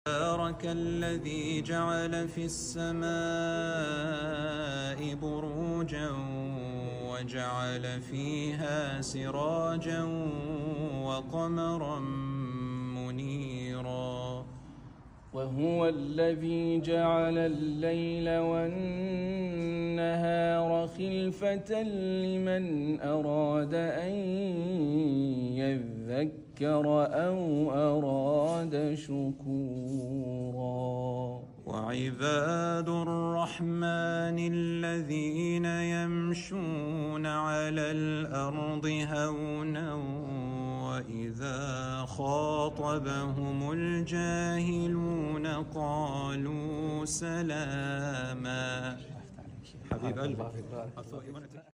Surah Al Furqan, Beautiful recitation I sound effects free download